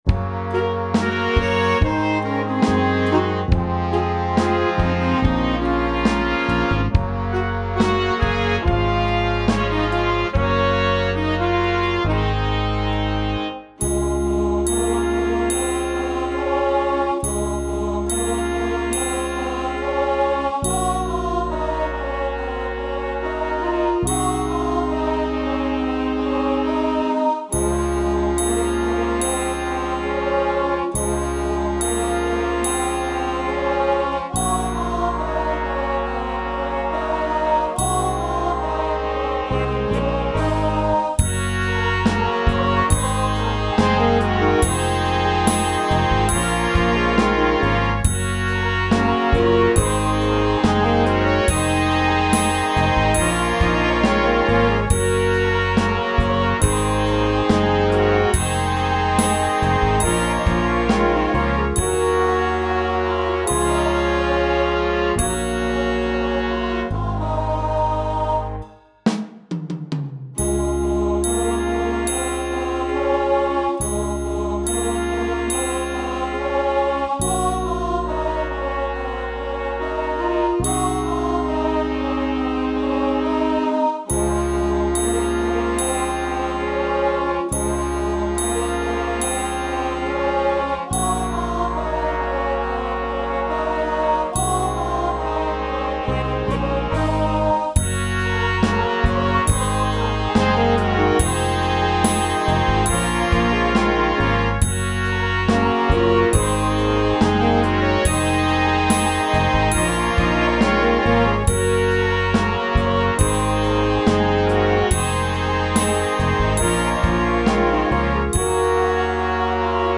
Dychová hudba Značky
Spev , Vianočné koledy a piesne Zdieľajte na